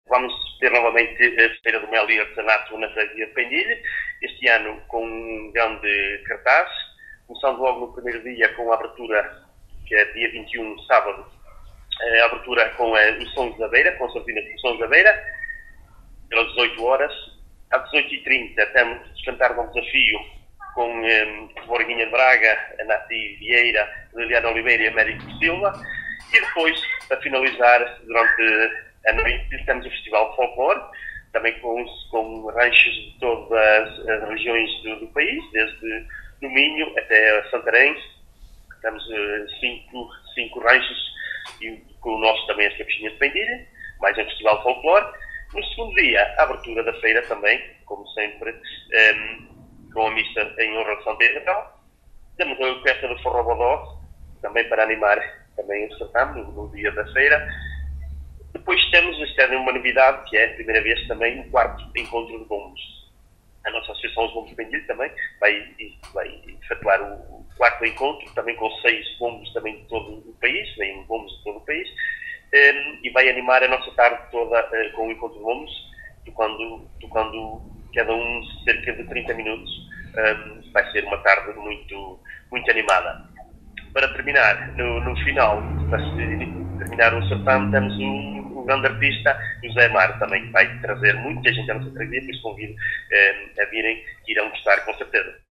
Jorge Cerdeira, Presidente da Junta de Freguesia de Pendilhe, em declarações à Alive FM, divulga em pormenor o programa festivo e deixa o convite para comparecerem neste certame.